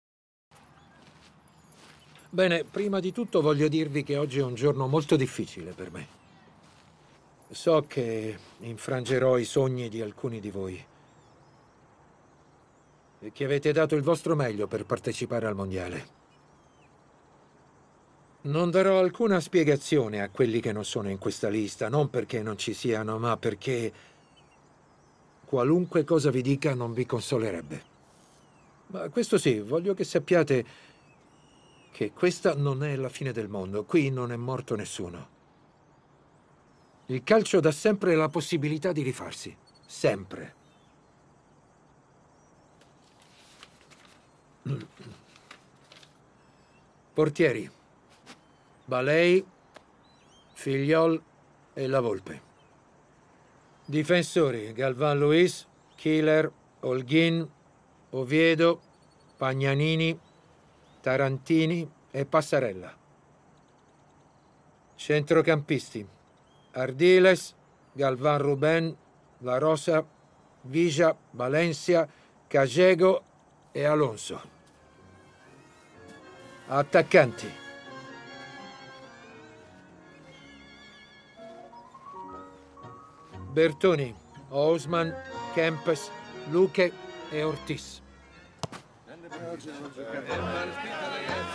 nel telefilm "Maradona: sogno benedetto", in cui doppia Dario Grandinetti.